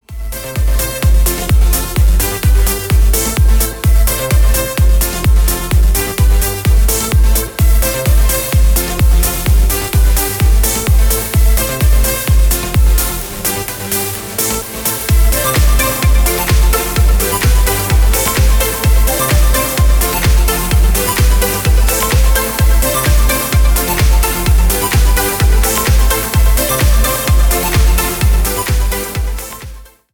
• Качество: 320 kbps, Stereo
Электроника
без слов